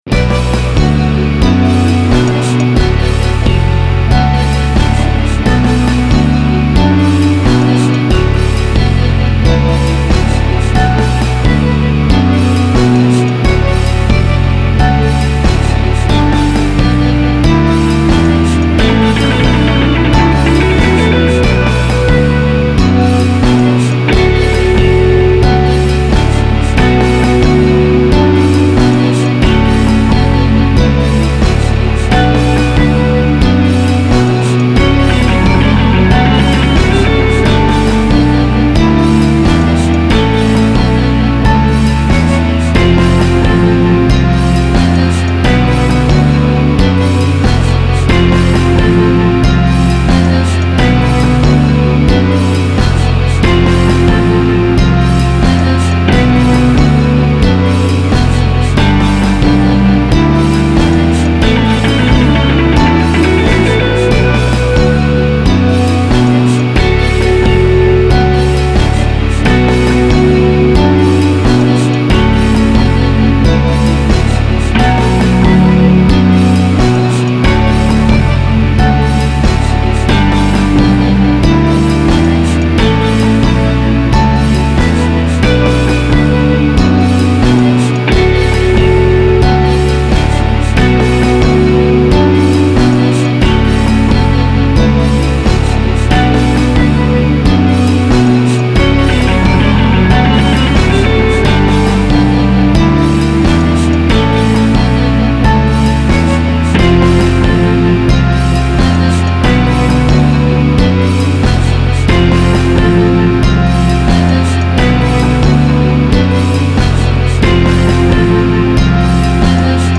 So, after doing the initial ReWire step and having a bit of FUN with VST effects plug-ins, I cloned the original 11 VSTi virtual instrument NOTION 3 score; zapped the MachFive 3 Vibrato Wurlitzer scripted instrument; replaced 3 of the existing instruments with a set of "sparkled" Latin percussion instruments; and did a bit more work on the Crunch Stratocaster, which included running it through a subtle Timesless 2 stereo echo unit set to do the echo repeats at the tempo of the song (90 BPM), which it determines through what appears to be magic, which is fine with me, since the Gestalt thing is coming along nicely, which is fabulous . . .
-- Basic Rhythm Section